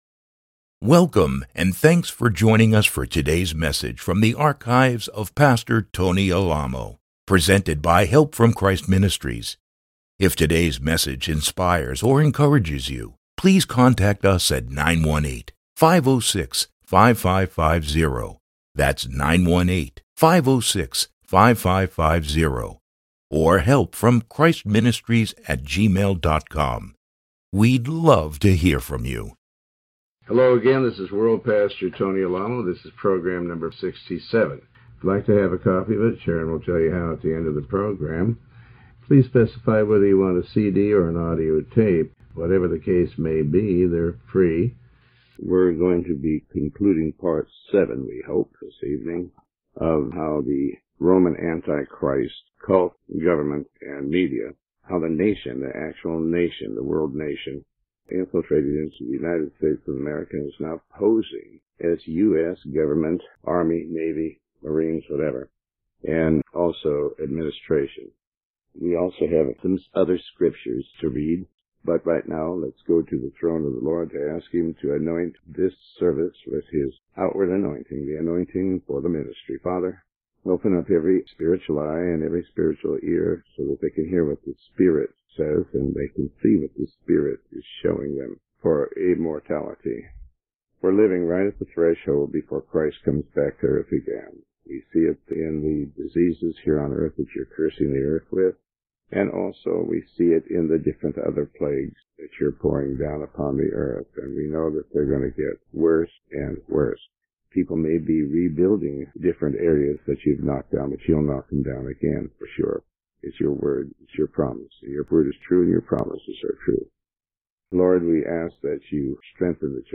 Sermon 67A